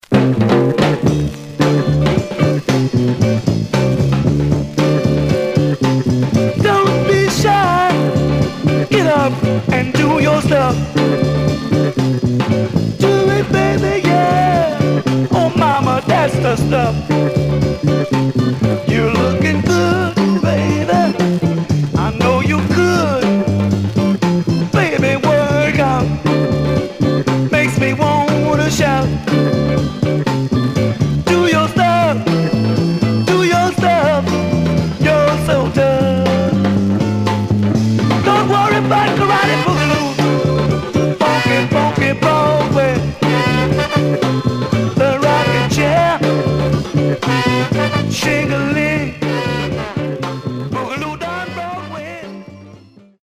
Condition: M- FUNK
Mono